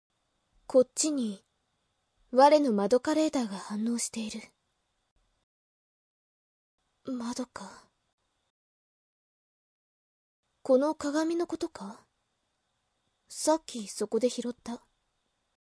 でも口調が武士。
なかなか喋らないが声はかわいい。